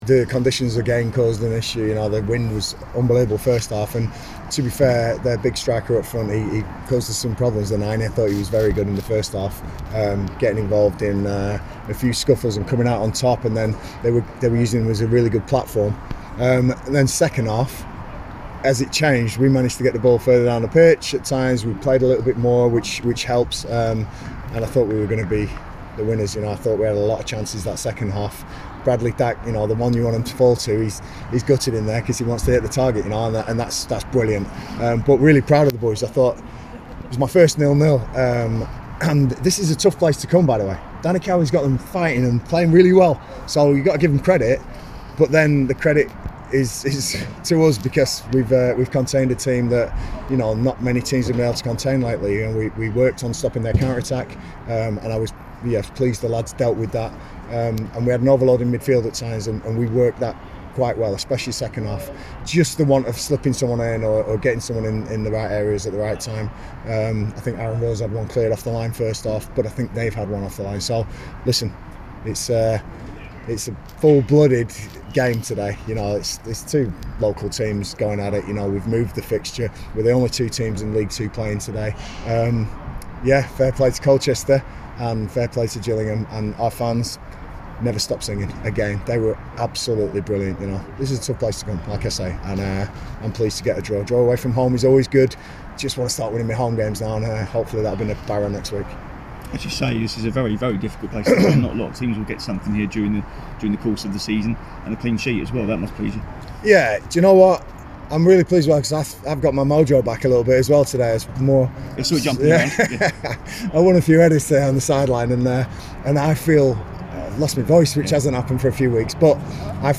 LISTEN: Gareth Ainsworth speaks after 0 - 0 draw with Colchester United